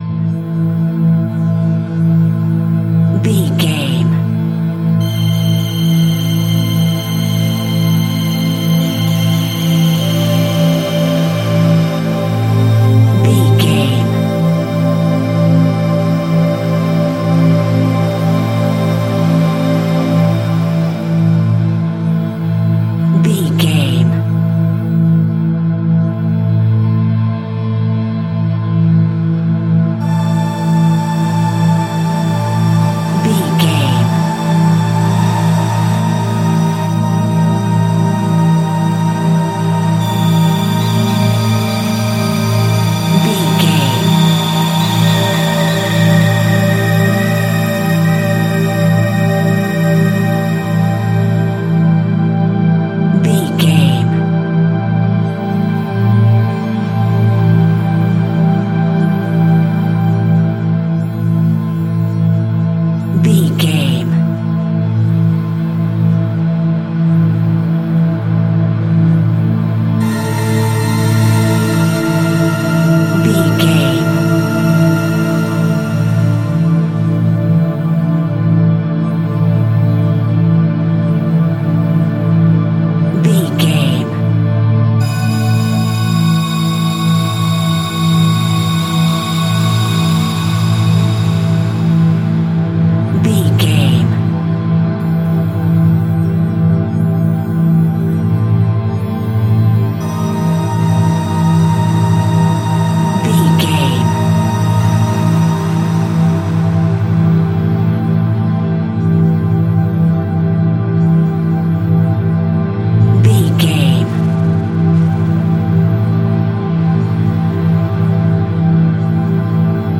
Horror Soundscape.
Aeolian/Minor
Slow
tension
ominous
dark
haunting
eerie
strings
synthesiser
ambience
pads
eletronic